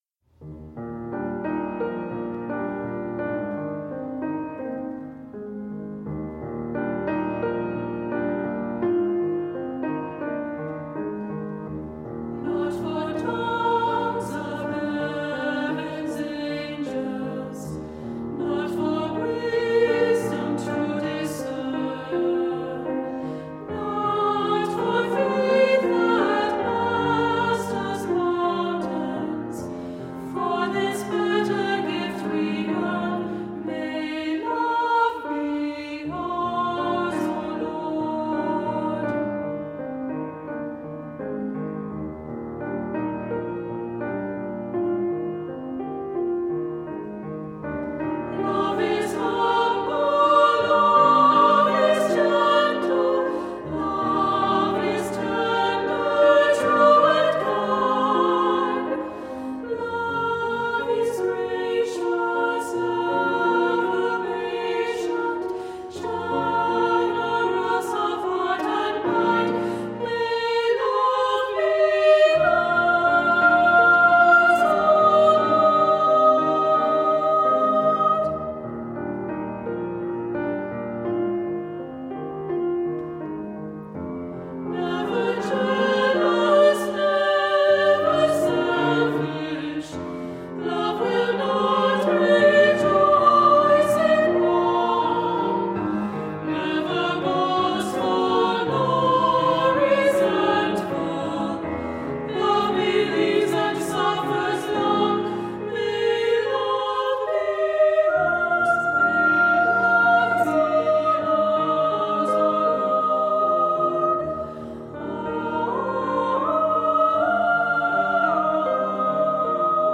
Voicing: Two-part equal; Two-part mixed